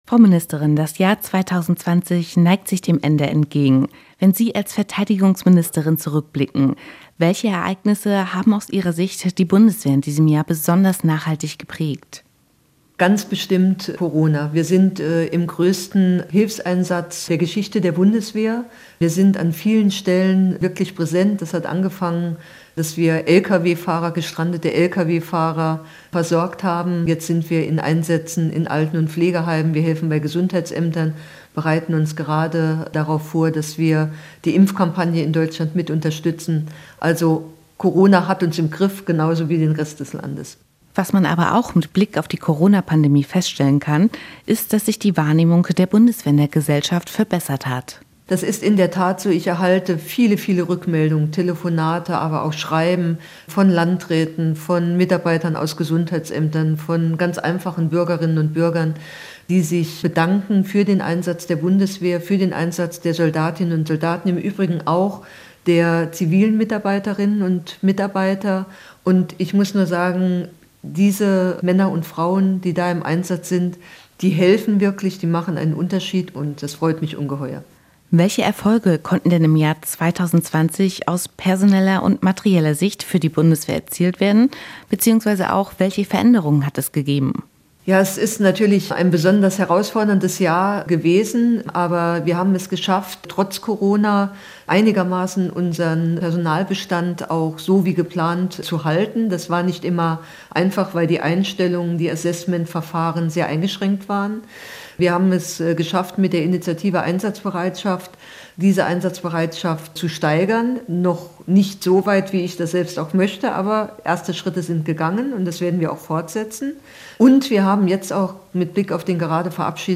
Fragen, die wir unserer Verteidigungsministerin Annegret Kramp-Karrenbauer im Jahresrückblick gestellt haben. Das Interview zum Jahresrück- und Ausblick können Sie hier nachhören.